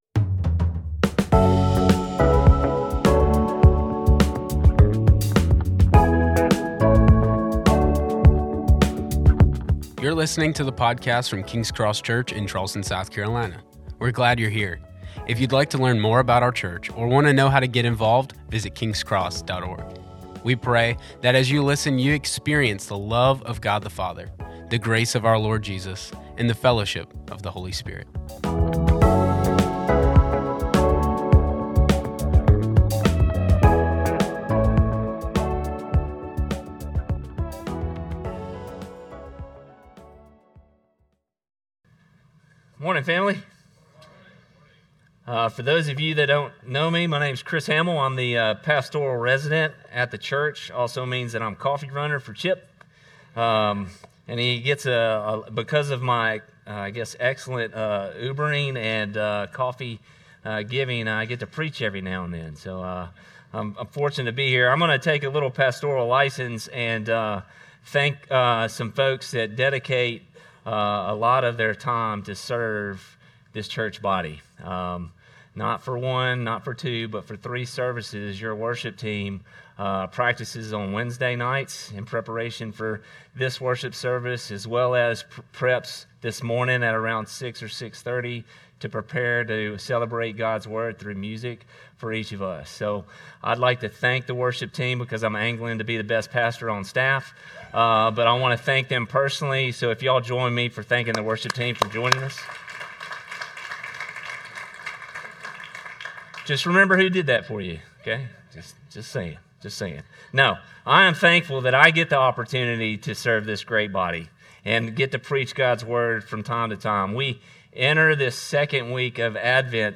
During this Christmas season, we may be seeking a promise of fulfillment. Discover the God of fulfilling promises in today's sermon.